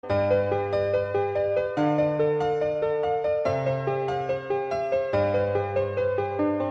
• Качество: 128, Stereo
громкие
электронная музыка
без слов
красивая мелодия
клавишные
пианино